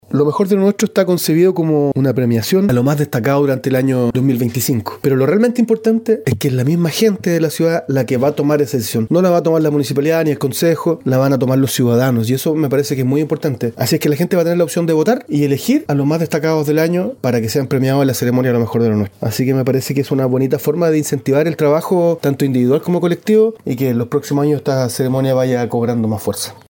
alcalde-Ruben-Mendez.mp3